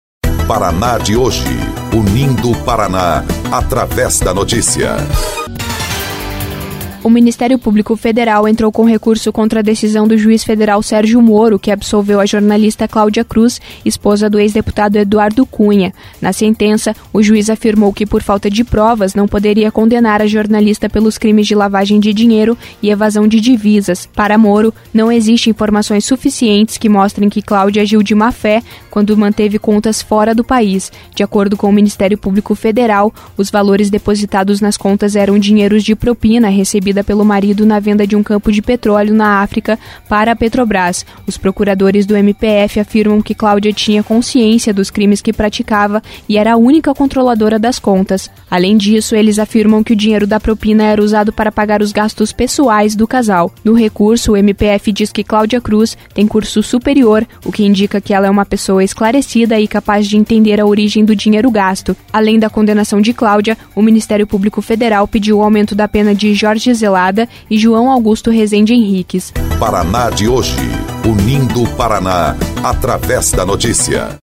06.07-–-BOLETIM-COM-TRILHA-–-MPF-entra-com-recurso-contra-decisão-de-Sérgio-Moro-no-processo-de-Cláudia-Cruz.mp3